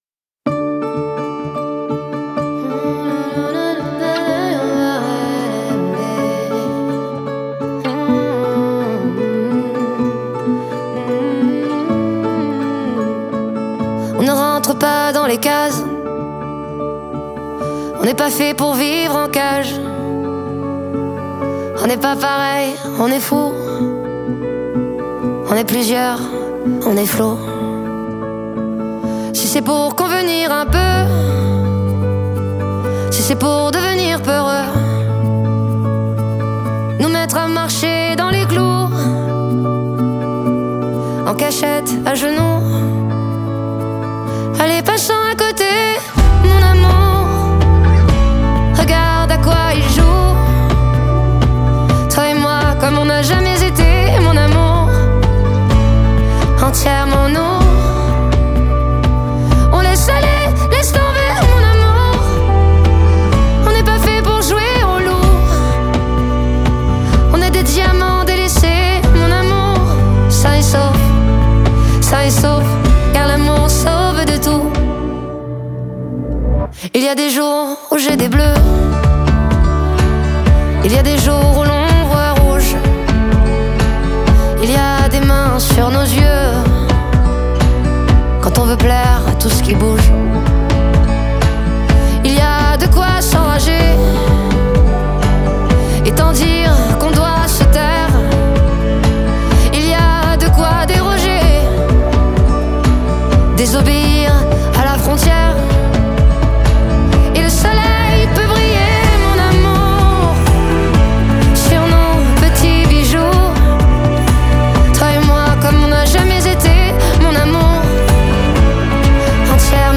lekkie folkowe melodie